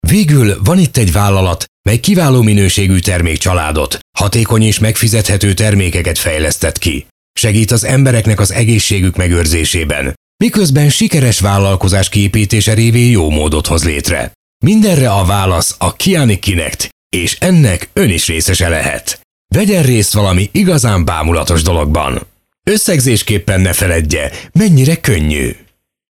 deep, cool, wry, energetic, announcer, sturdy, authoritative, corporate,
Sprechprobe: eLearning (Muttersprache):